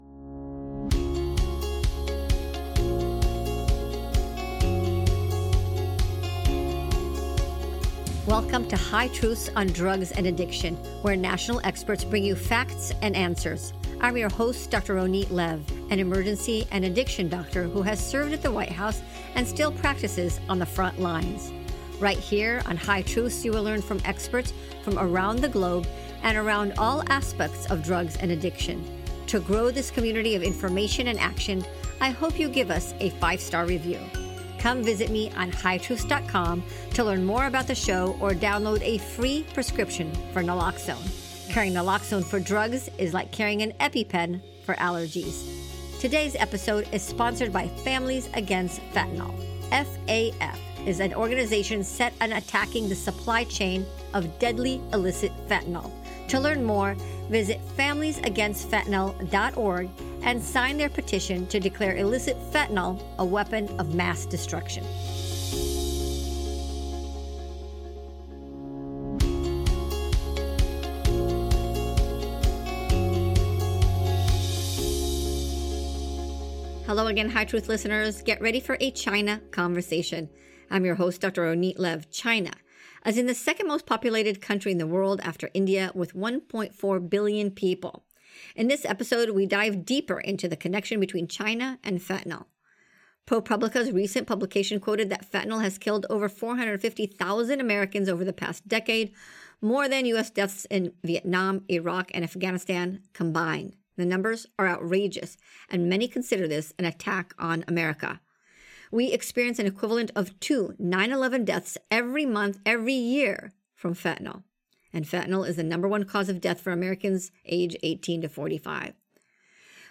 a fascinating discussion